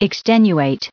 added pronounciation and merriam webster audio
1404_extenuate.ogg